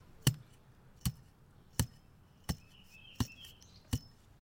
Pipe hitting ground
digging effect ground hitting pipe sound sound effect free sound royalty free Sound Effects